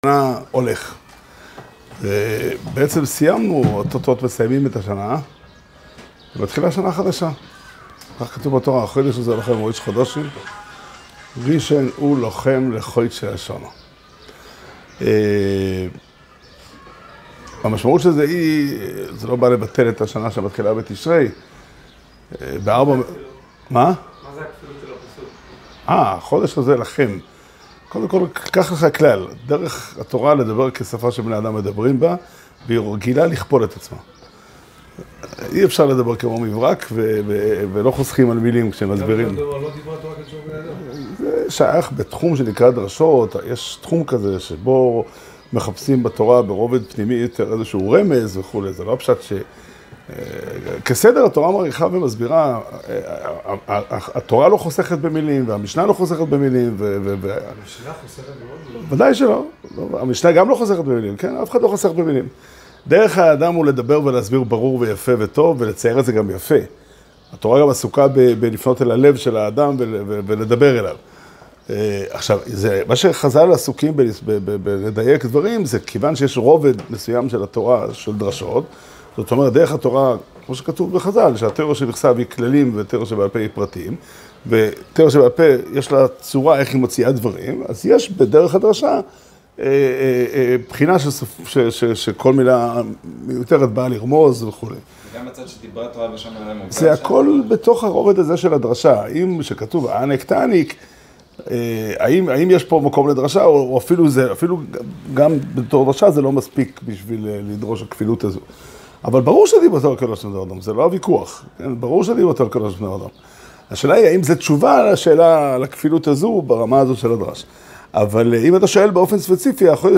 שיעור שנמסר בבית המדרש פתחי עולם בתאריך י"ח אדר תשפ"ה